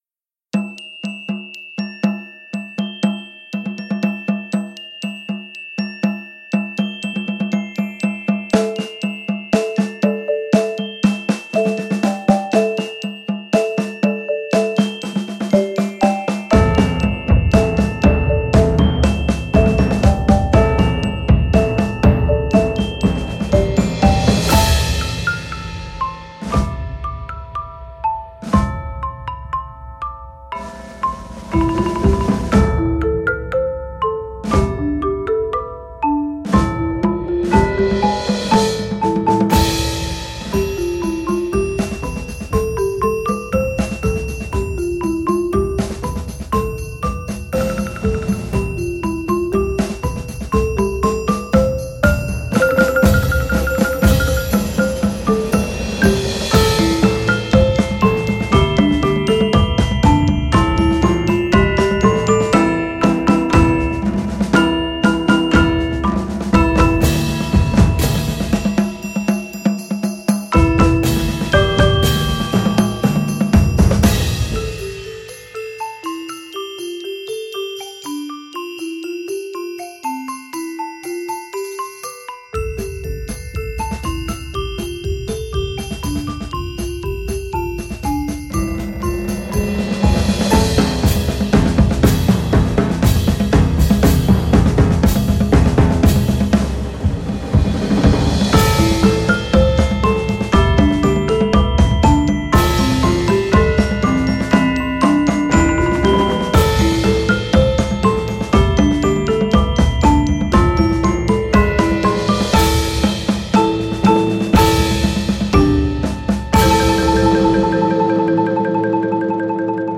Voicing: 12 Percussion